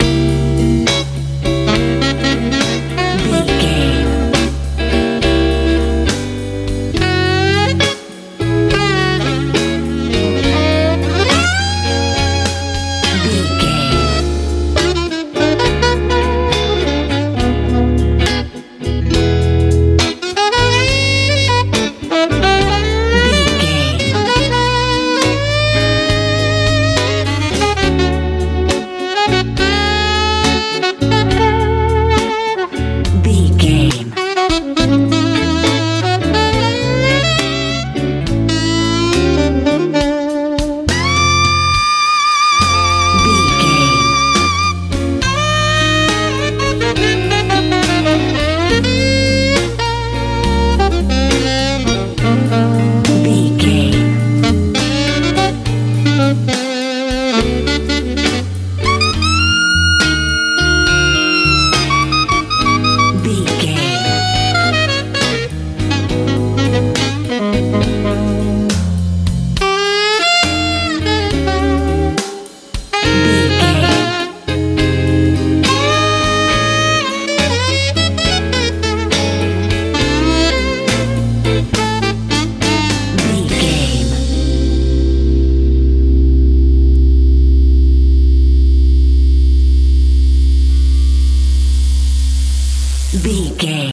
blues rock
Locrian
C♯
groovy
funky
saxophone
electric guitar
bass guitar
drums
soothing
smooth
sensual